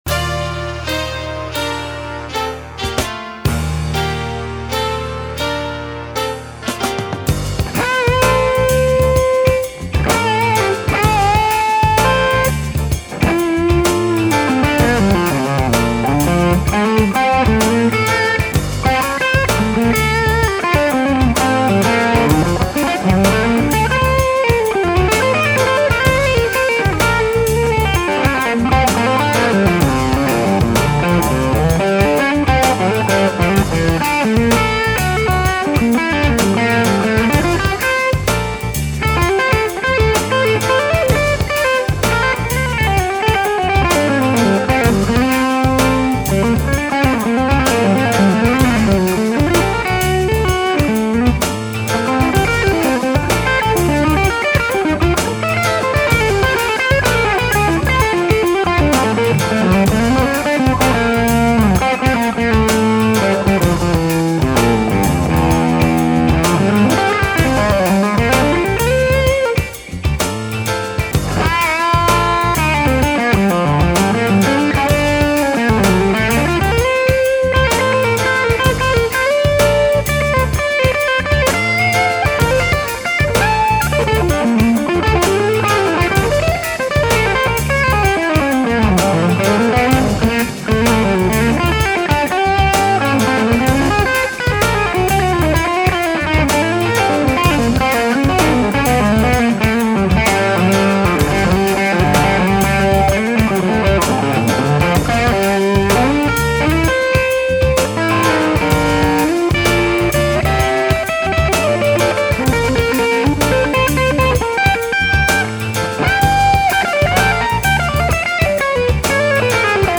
Fwiw, this is a Baker B1, not the RF.
G1265 and SM57 dead on but closer to edge than center.
Treble at 10, mid at 1, bass at 1!!!!
No PAB